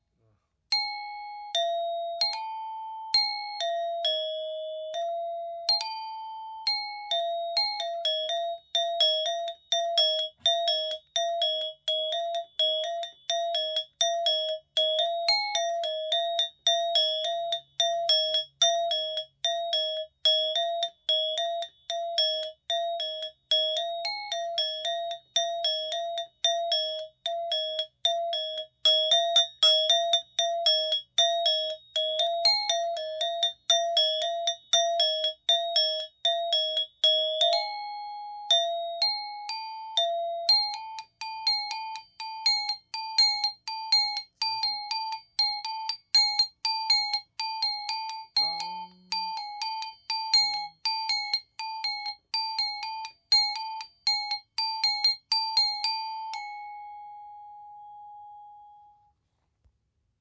I play Balinese gamelan music in Los Angeles as a member of Sanggar Tujunga.
Rehearsal Recordings